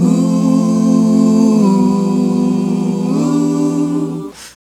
Ooo 152-E.wav